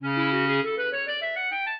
clarinet
minuet5-6.wav